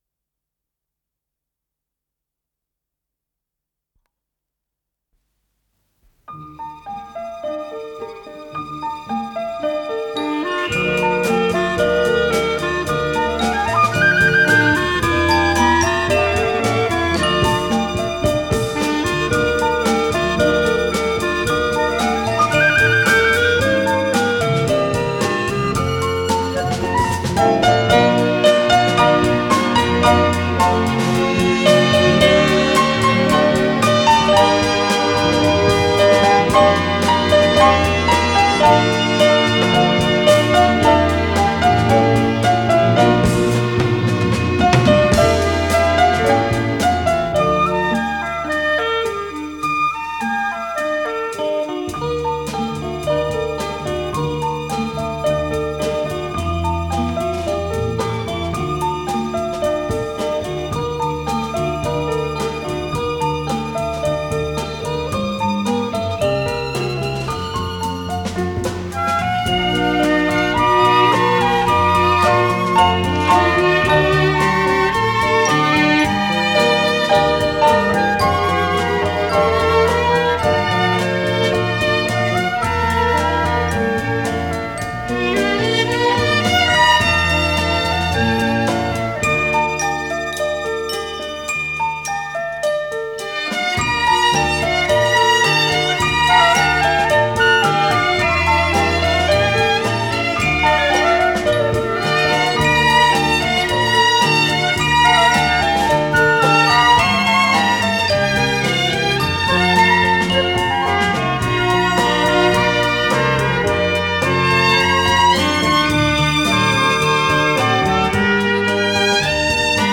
ПодзаголовокЗаставка, ре минор
ВариантДубль моно